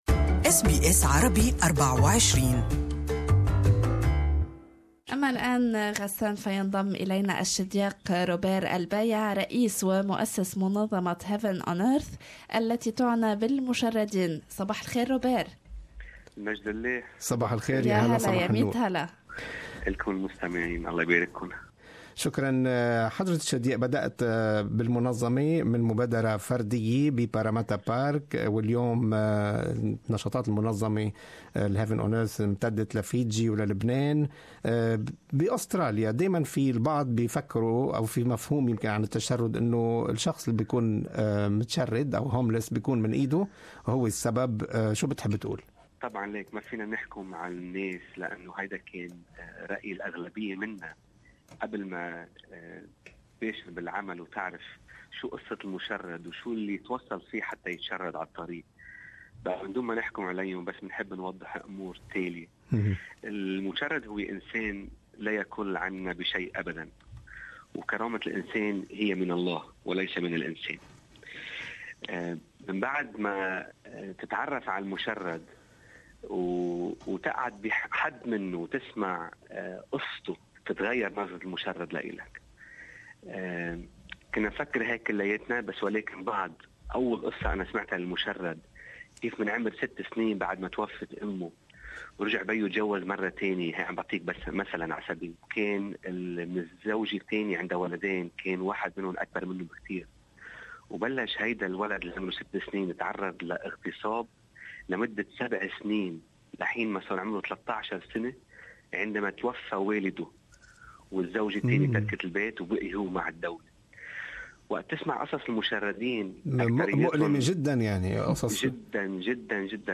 More in this interview on the occasion of World Homeless Day.